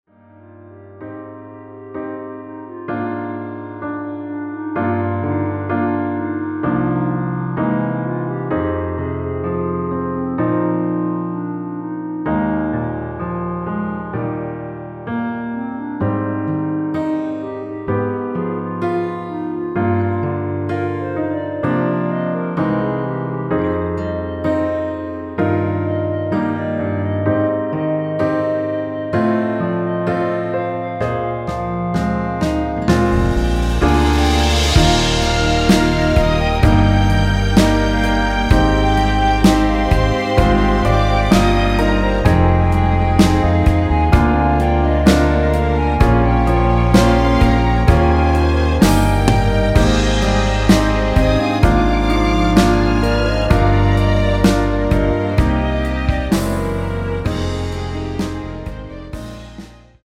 1절후 후렴으로 진행되게 편곡 되었습니다.(본문의 가사 참조)
원키(1절+후렴)멜로디 포함된 MR입니다.
Eb
앞부분30초, 뒷부분30초씩 편집해서 올려 드리고 있습니다.